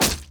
Spell Impact 2.wav